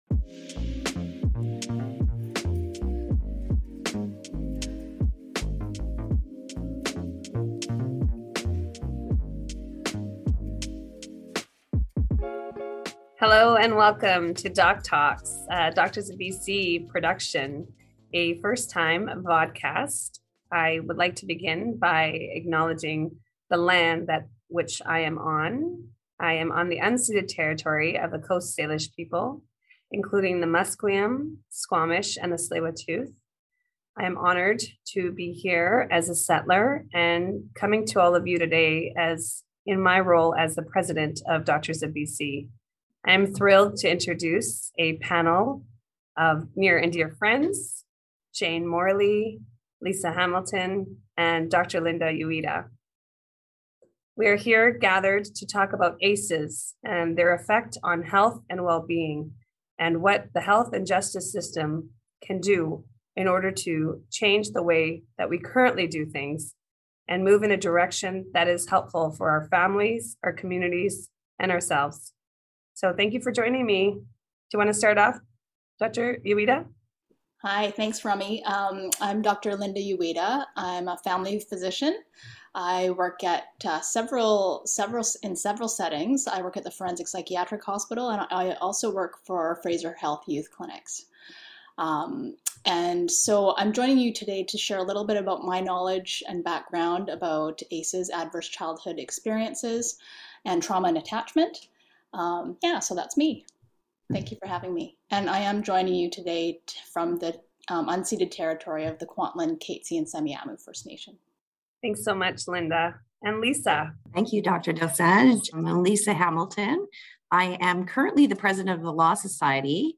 This episode is audio taken from a video recording.